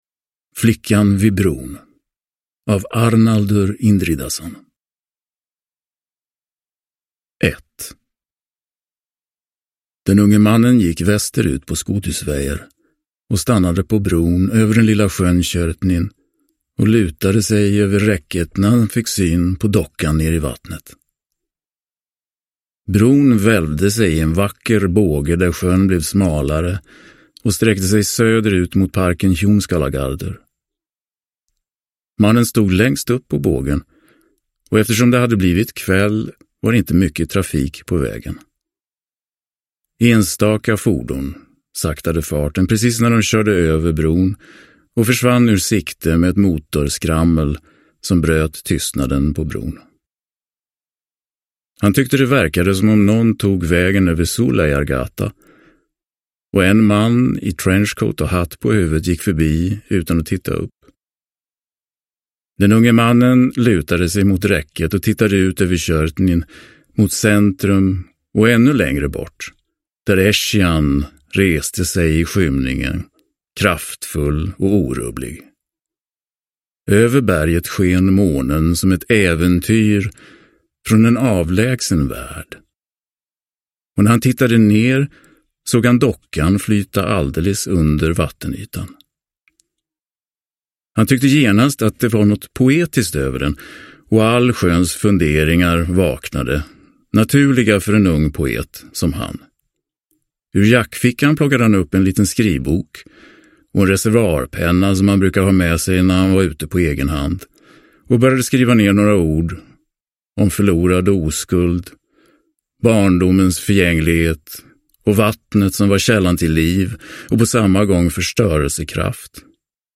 Uppläsare